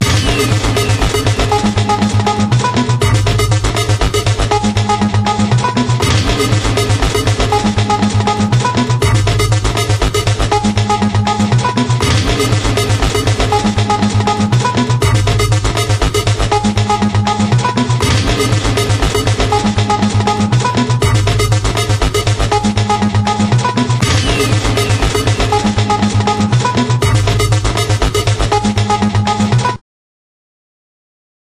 Voilà. La stéréo est mise, et amélioration de l'ensemble.